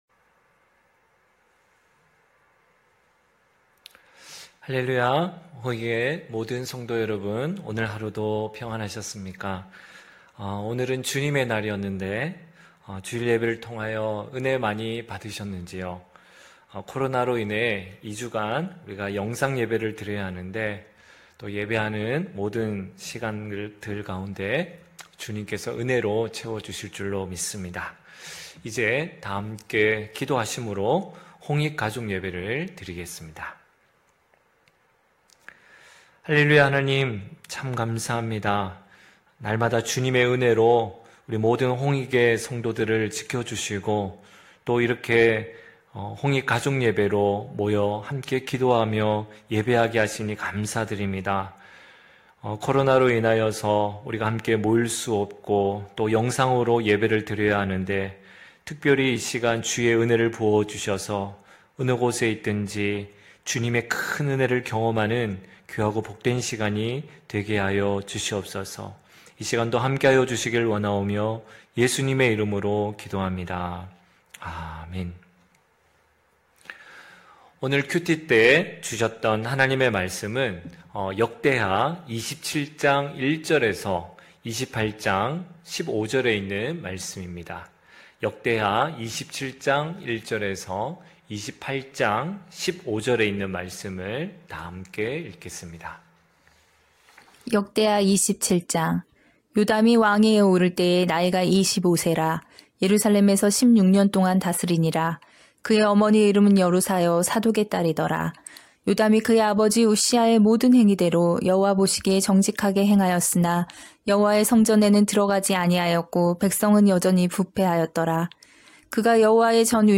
9시홍익가족예배(12월6일).mp3